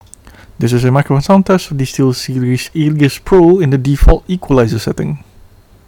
Mic Test
I’m amazed how big of a difference the sound can be as the latter feels more like your studio podcast-style voice that you’d typically get with dynamic microphones when speaking rather close. Unsurprisingly as it’s a condenser microphone, there is a slight echo to my voice as I’m not recording in a treated room.